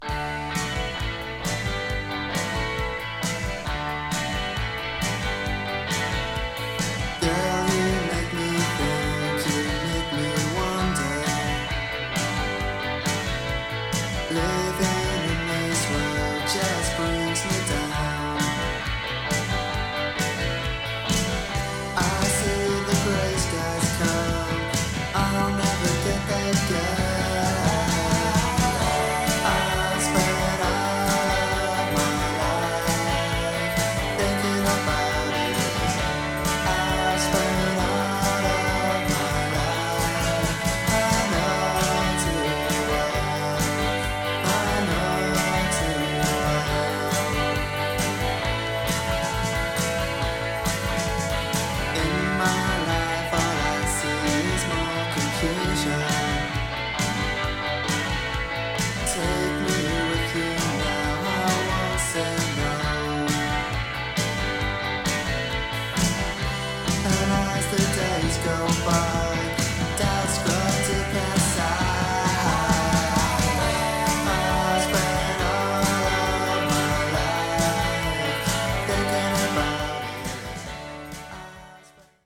スコティッシュ・インディー・ポップ